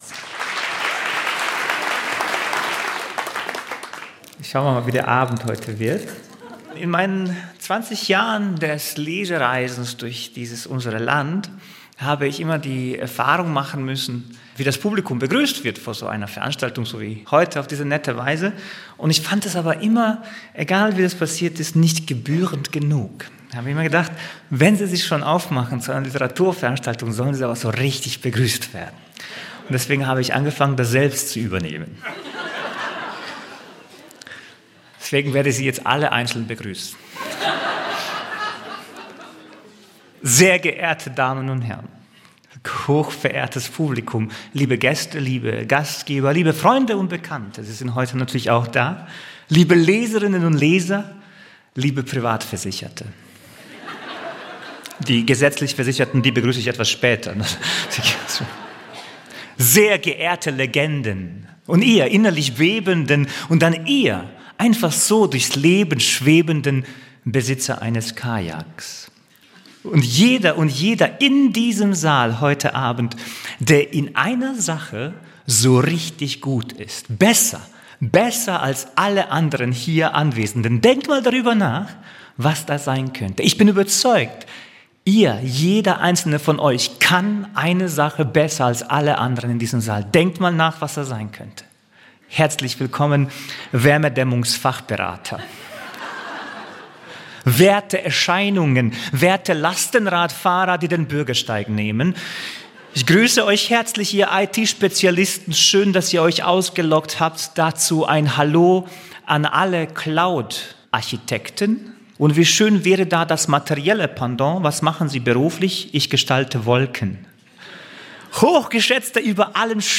Saša Stanišić feiert die Rede als literarische Waffe, kritisiert Doppelmoral und spricht beim Festival lesen.hören in Mannheim.